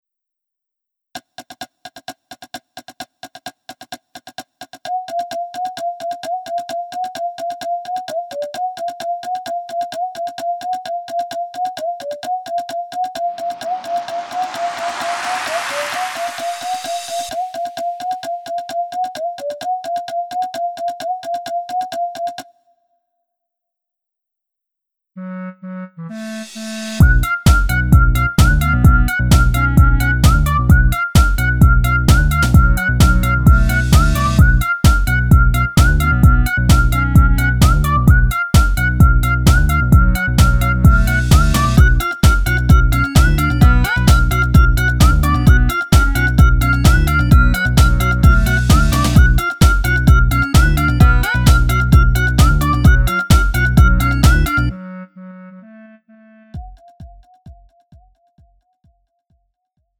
음정 -1키 1:48
장르 가요 구분 Lite MR